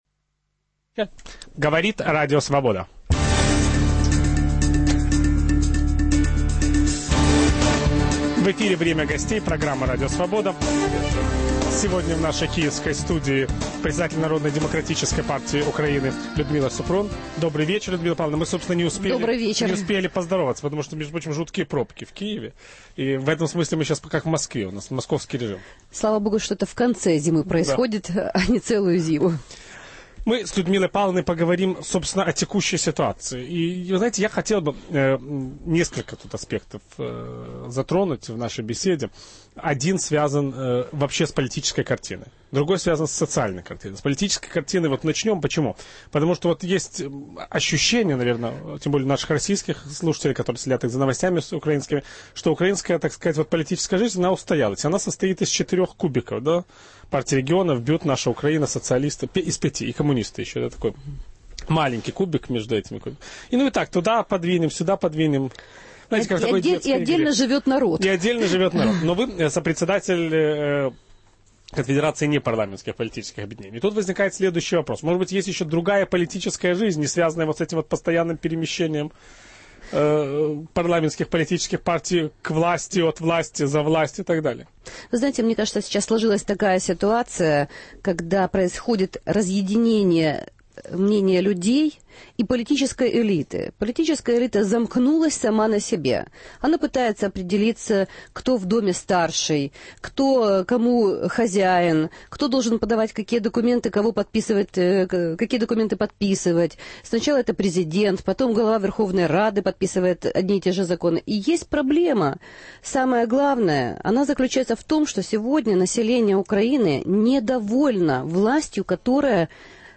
В Киевской студии Радио Свобода - председатель Народно-демократической партии Украины Людмила Супрун.